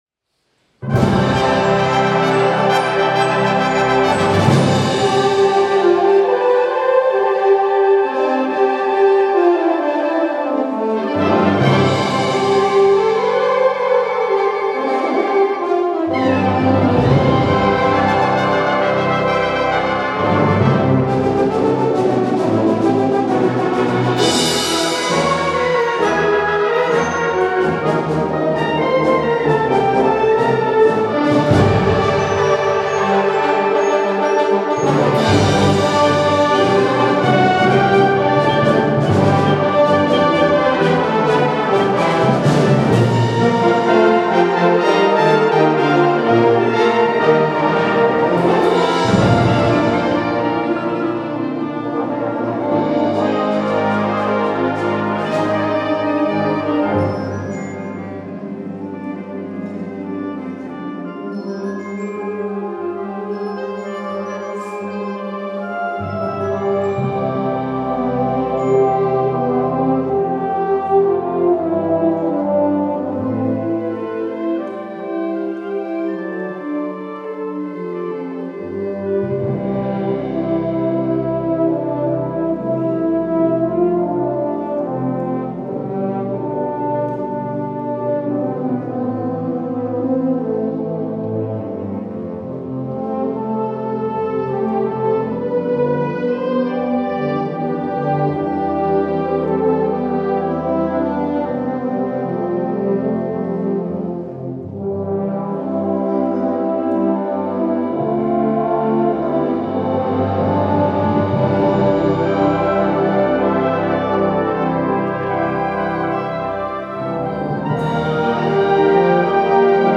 Genre: Blasmusik.